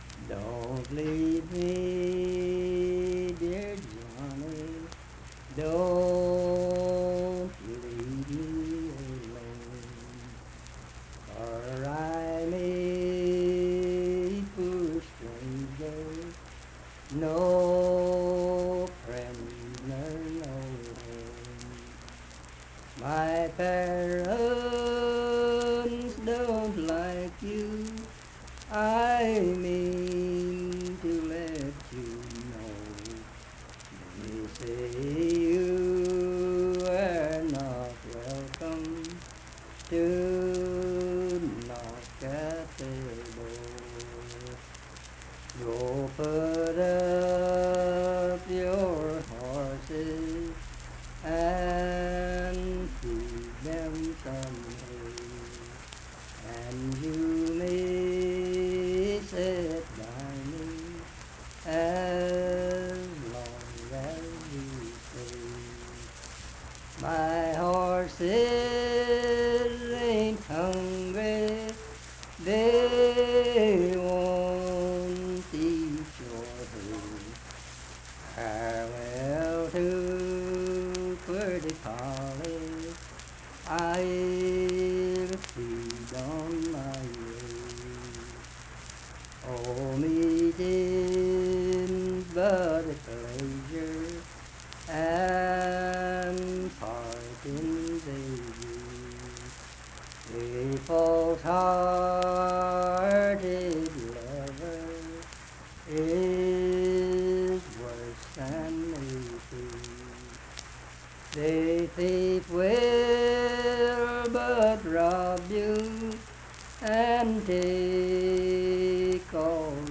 Unaccompanied vocal music
Verse-refrain 4d(4).
Voice (sung)
Lincoln County (W. Va.), Harts (W. Va.)